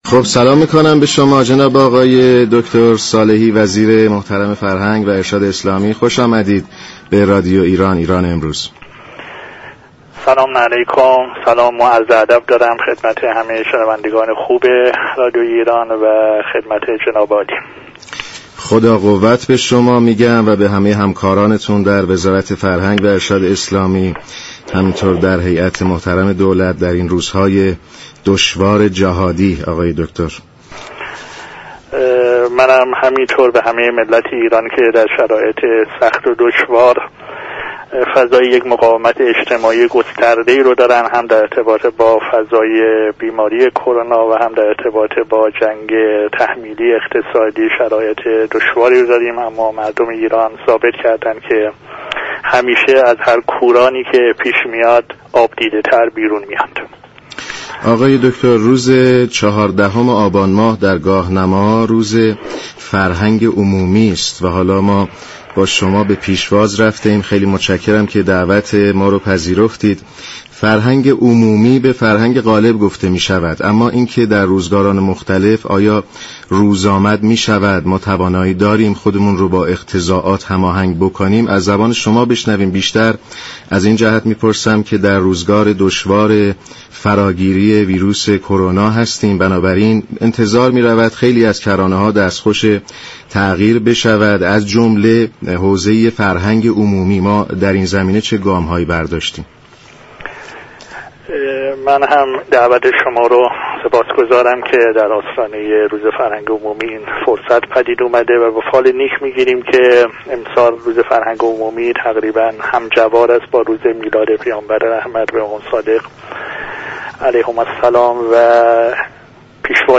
به گزارش شبكه رادیویی ایران، برنامه ایران امروز در آستانه این روز با دكتر سید عباس صالحی وزیر فرهنگ و ارشاد اسلامی گفت و گو كرده است